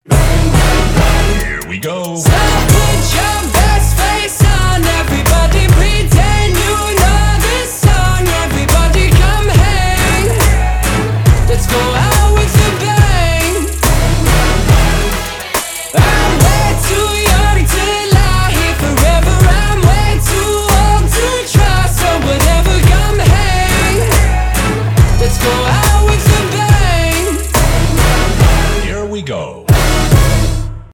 громкие
indie pop , alternative